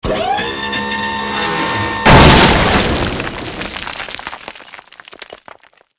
crushbrick.wav